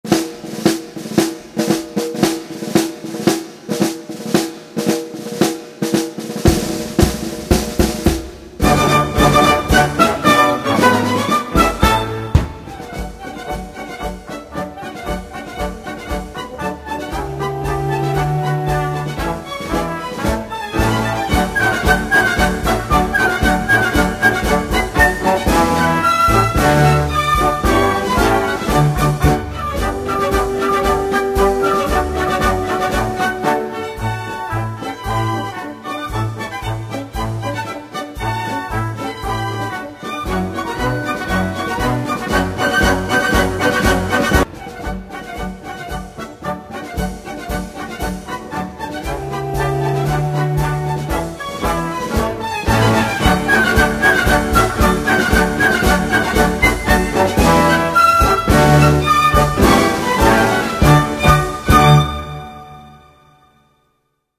Stehgeige und Solovioline
Violine
Violoncello
Kontrabaß
Flöte
Oboe
Klarinette
Trompete
Horn
Posaune
Schlagwerk
Klavier
Sopran